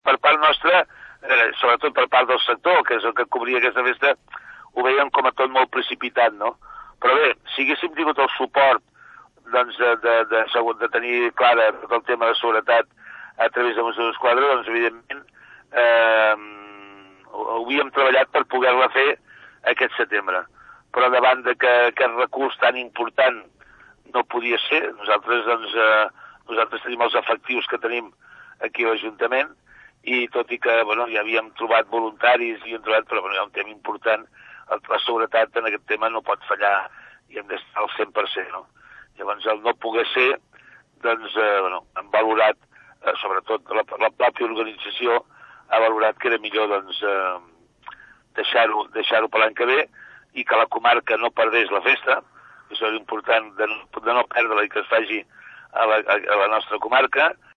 Joan Campolier, alcalde de Santa Susanna, ho explica així.